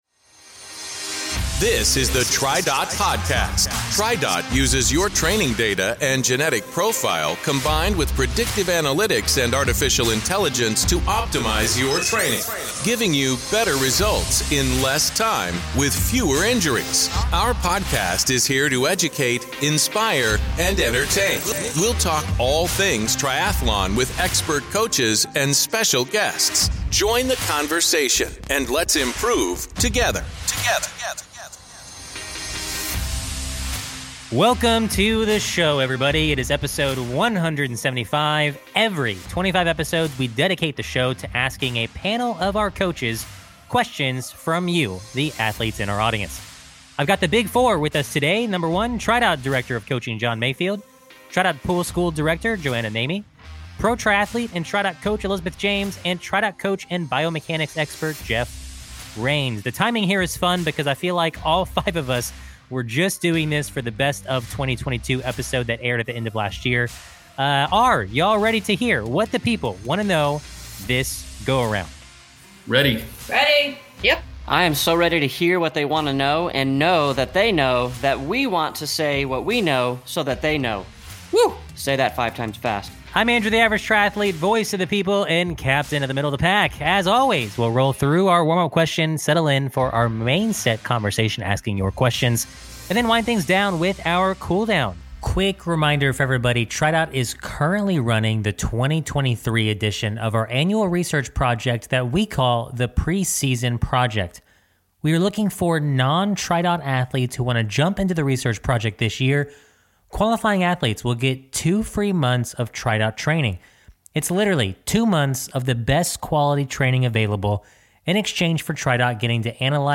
Listen in as the coaches talk about mobility versus strength sessions, adjusting your training after a poor night (or several nights) of sleep, and how much time you should spend in aero on your indoor workouts. They also discuss overcoming swim panic and a hot new metric: VLaMax.